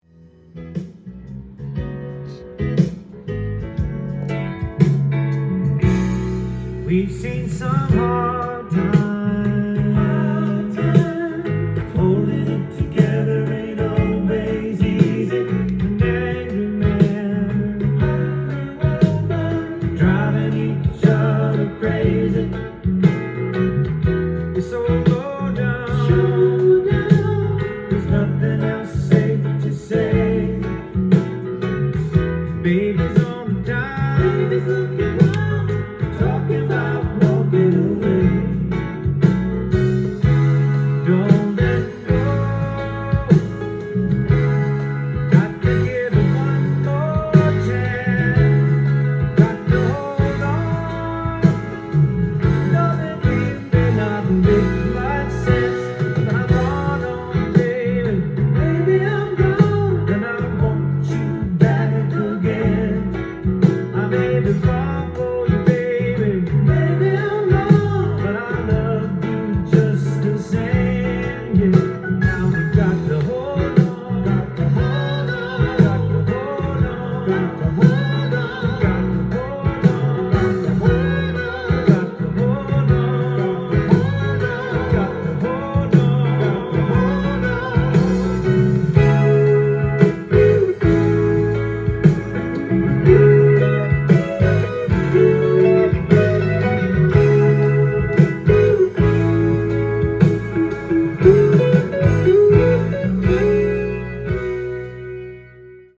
ジャンル：AOR
店頭で録音した音源の為、多少の外部音や音質の悪さはございますが、サンプルとしてご視聴ください。